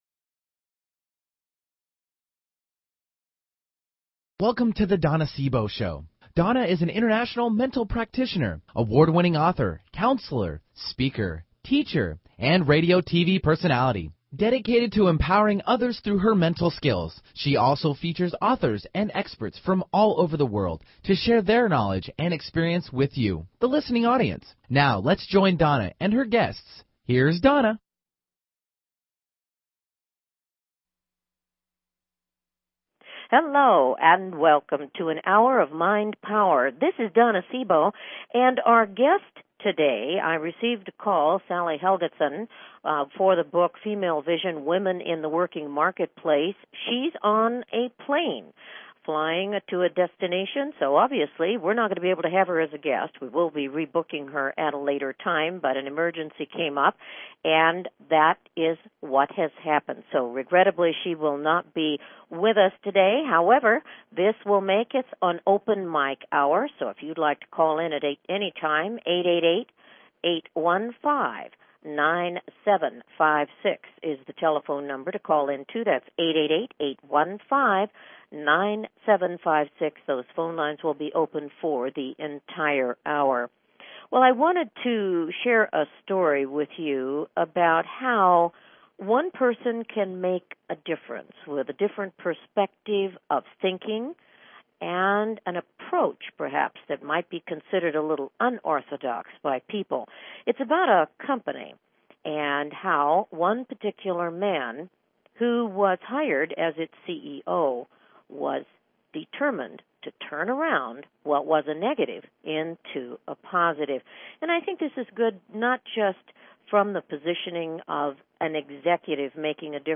Talk Show Episode
Her interviews embody a golden voice that shines with passion, purpose, sincerity and humor.
Tune in for an "Hour of Mind Power". Callers are welcome to call in for a live on air psychic reading during the second half hour of each show.